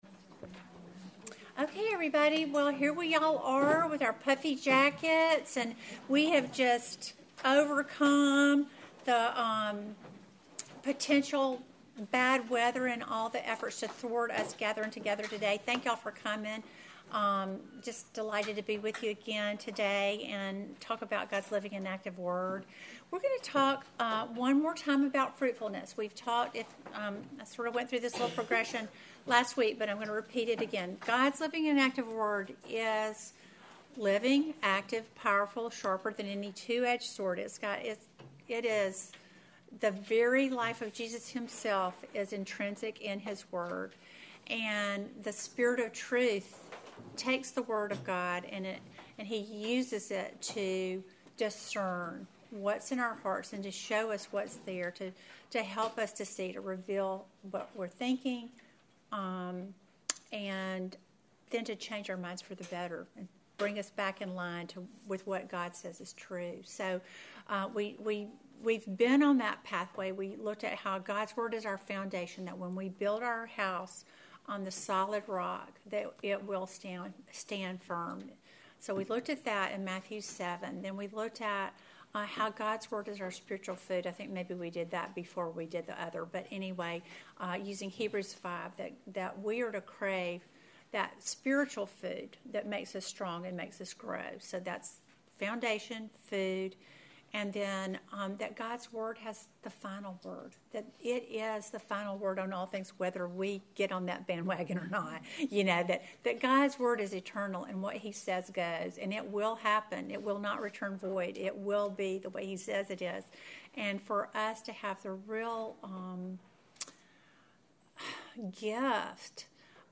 Welcome to the tenth lesson in our series GOD’S LIVING AND ACTIVE WORD!